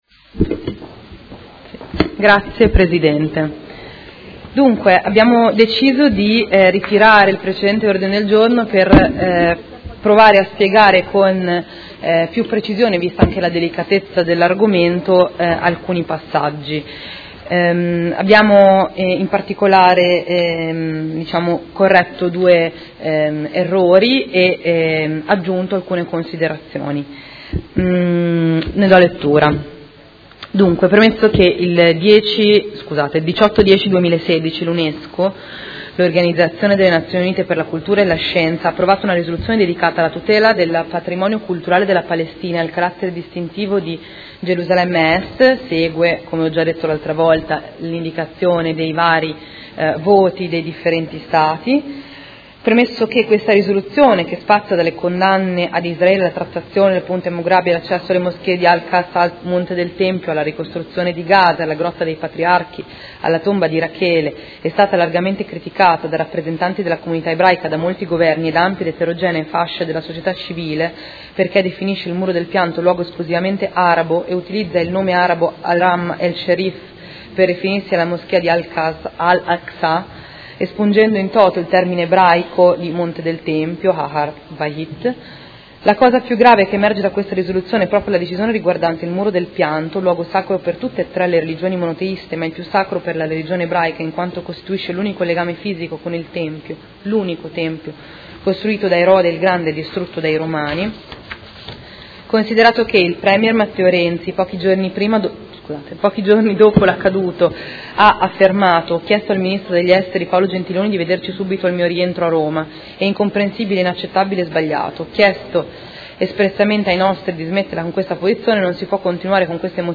Federica Di Padova — Sito Audio Consiglio Comunale